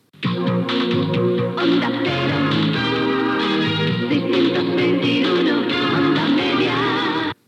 Indicatiu cantat de l'emissora a la freqüència 621 KHz OM